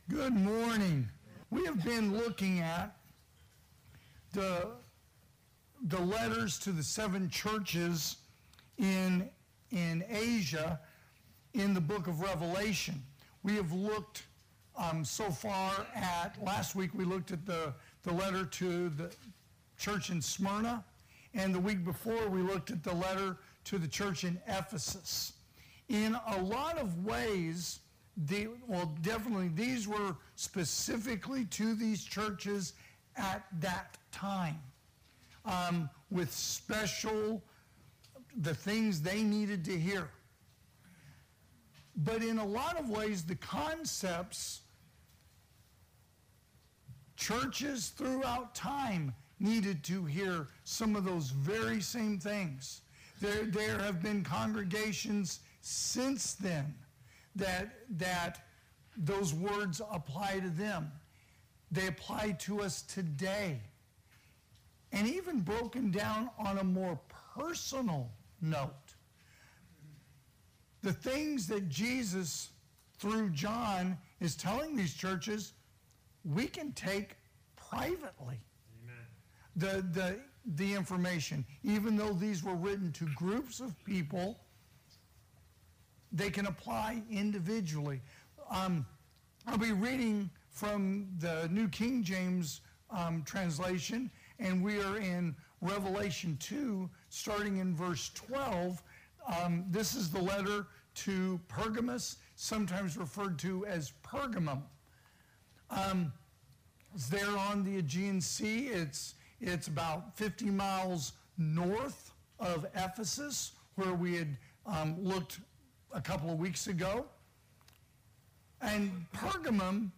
2025 (AM Worship) “The Church At Pergamos”